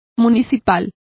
Complete with pronunciation of the translation of civic.